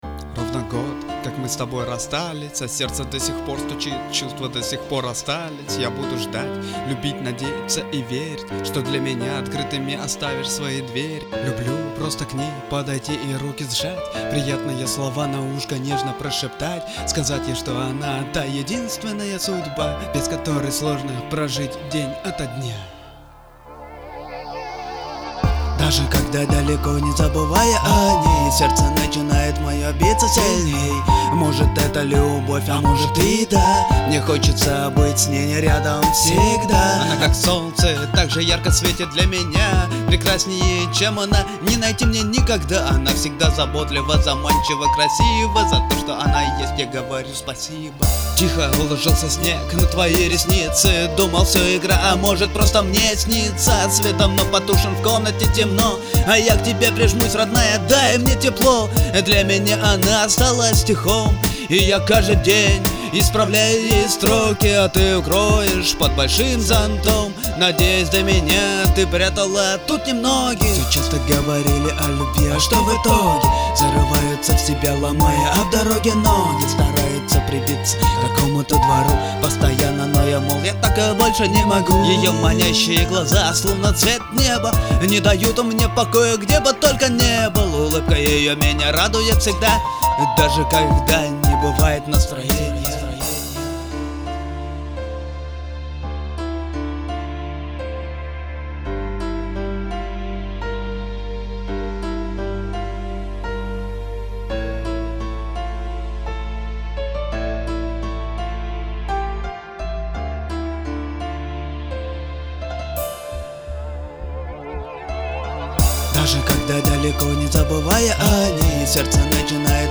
Сэмплы: Свои, стихи свои